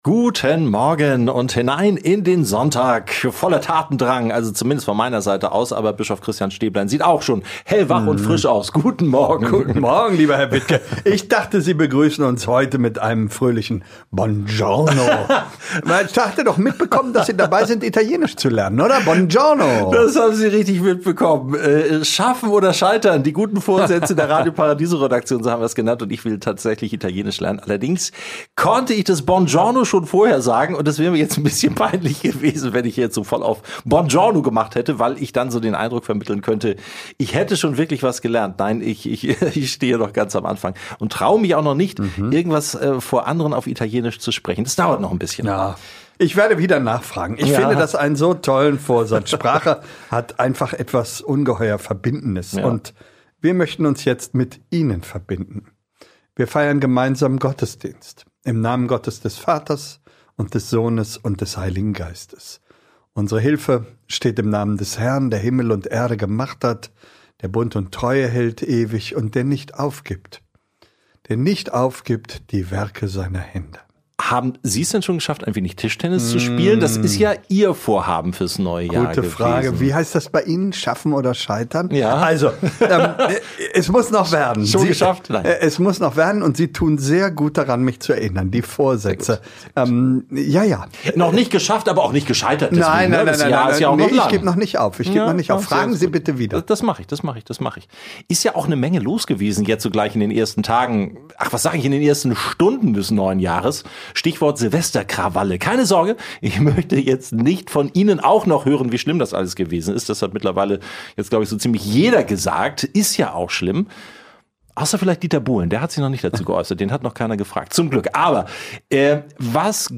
GiG - Fügung und Führung ~ Radio Paradiso - Gottesdienst im Gespräch Podcast